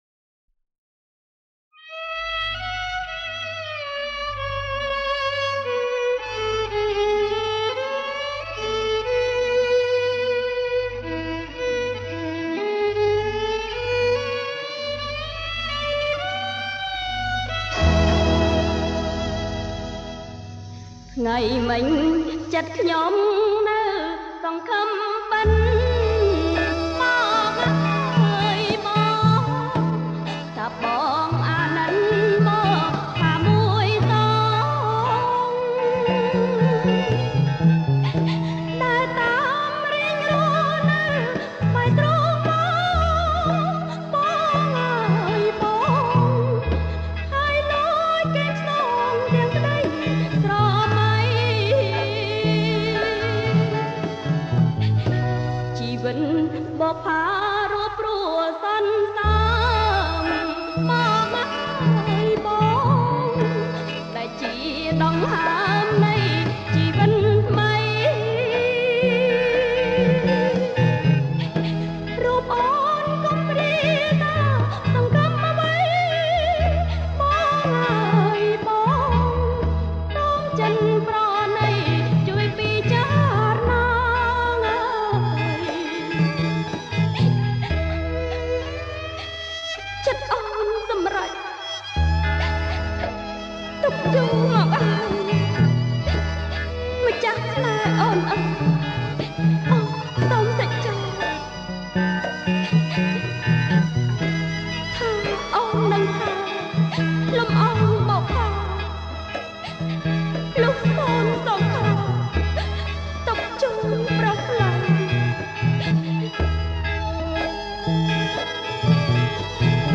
• ប្រគំជាចង្វាក់ Blue Folk